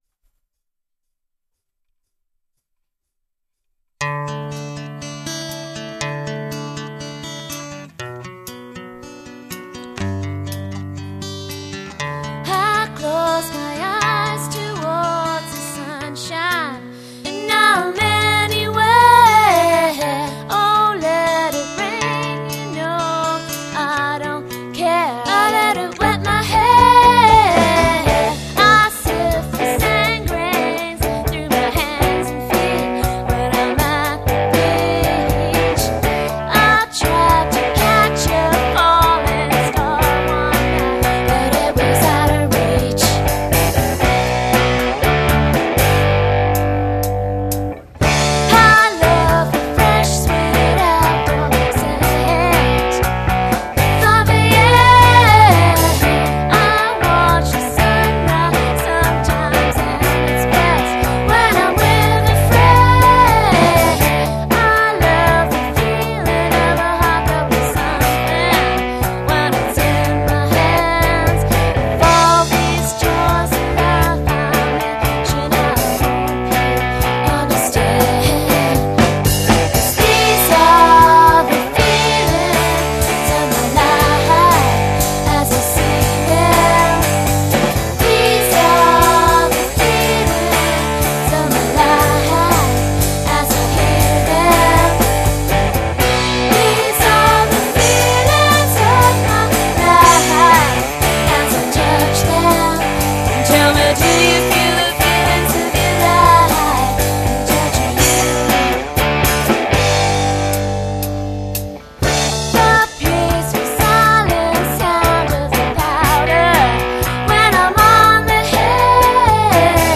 Cornerstone is a local group of musicians playing some of today's best rock, alternative radio played and original music.